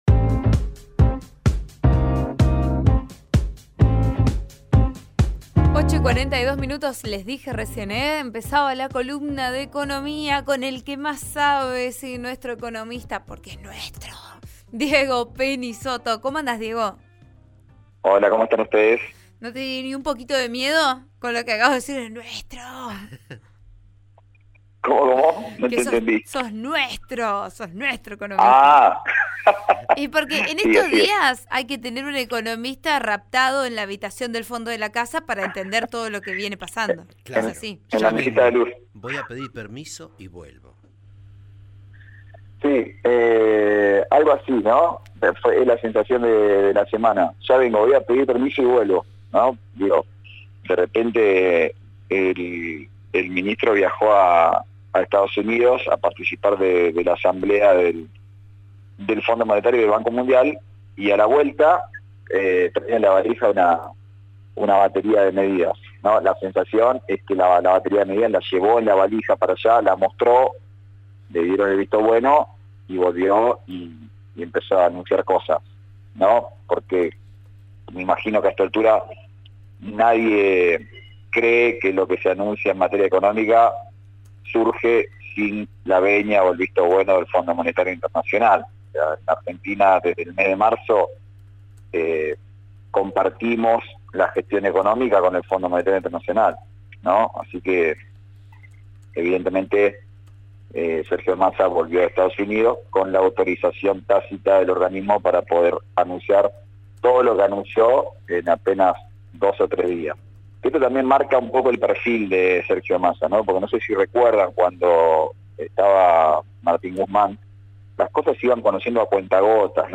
El presidente Alberto Fernández anunció el refuerzo económico para sectores vulnerables, un nuevo IFE, que el Gobierno tenía en evaluación, junto a una serie de medidas adoptadas en coordinación con el ministro de Economía, Sergio Massa. Lo hizo durante el acto de inauguración de obras en Cañuelas con el que se conmemoró el Día de la Lealtad Peronista del 17 de Octubre, donde además cuestionó con dureza al expresidente Mauricio Macri.